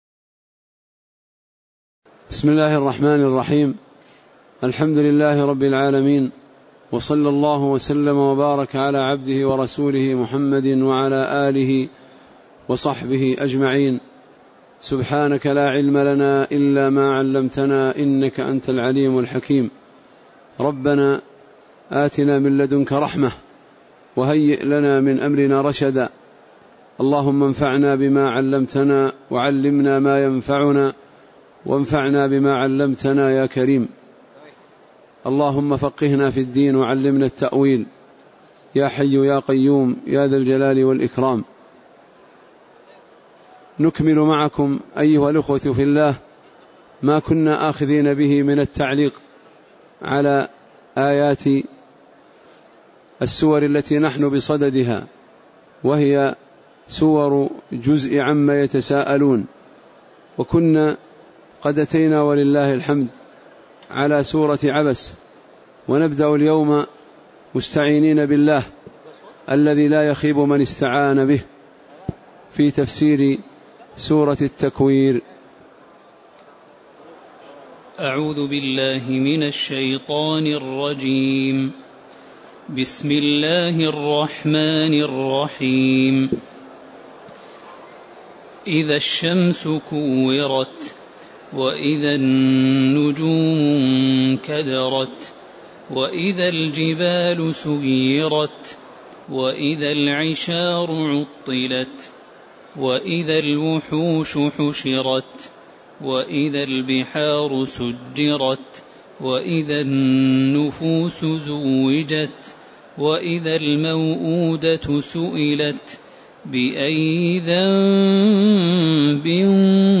تاريخ النشر ١٥ شوال ١٤٣٨ هـ المكان: المسجد النبوي الشيخ